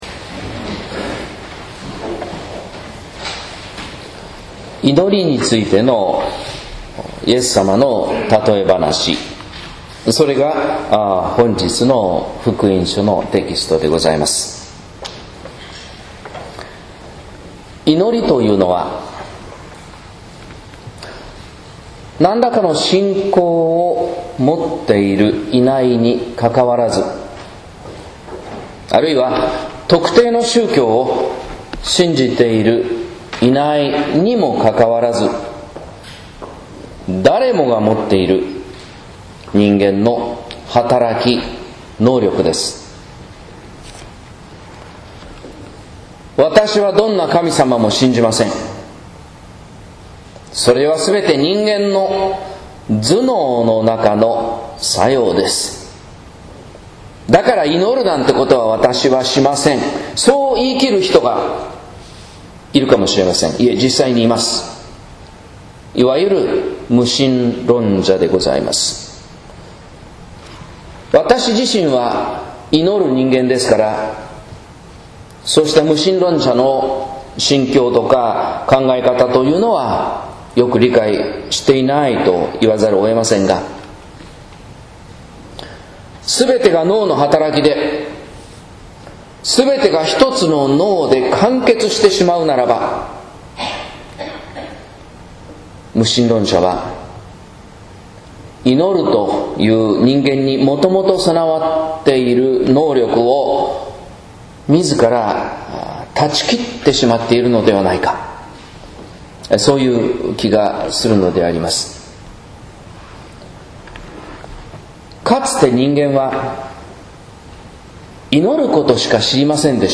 説教「祈りが人を人にする」（音声版） | 日本福音ルーテル市ヶ谷教会